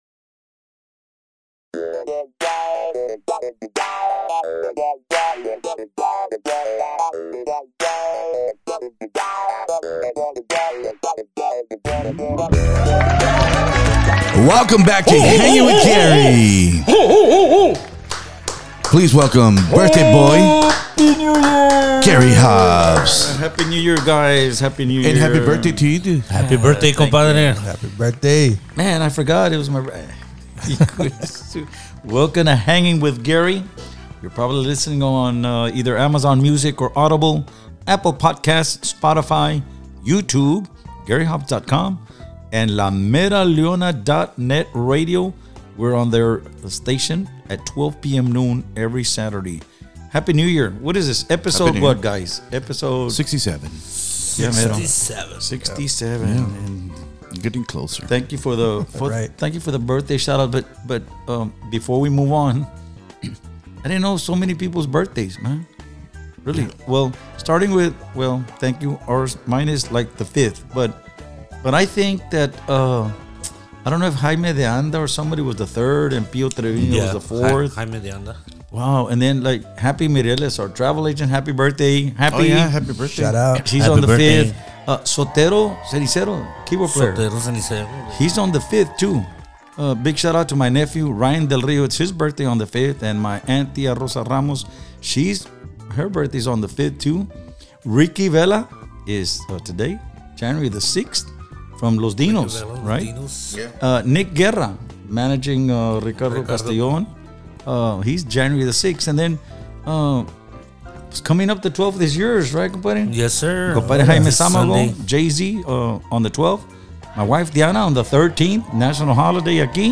Tejano Music Legend Gary Hobbs talks all about Gary Hobbs.
Be a guest on this podcast Language: en Genres: Music , Music Interviews Contact email: Get it Feed URL: Get it iTunes ID: Get it Get all podcast data Listen Now...